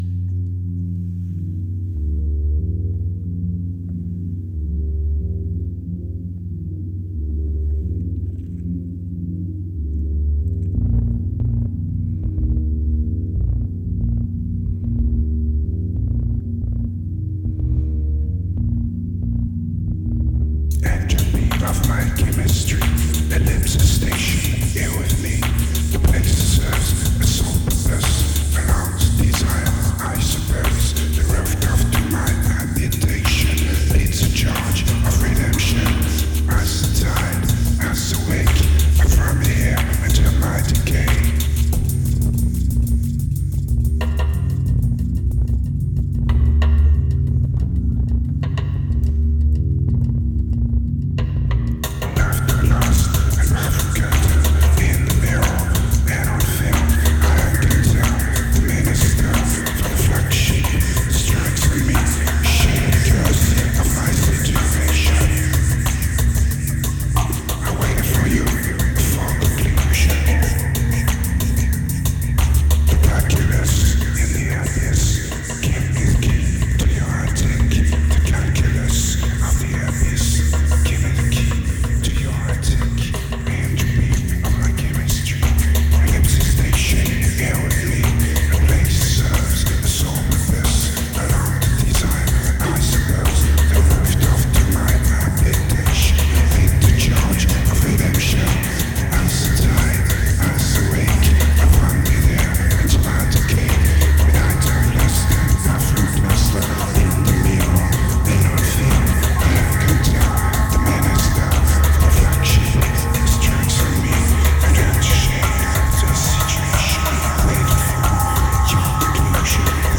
1806📈 - 27%🤔 - 92BPM🔊 - 2013-03-09📅 - -30🌟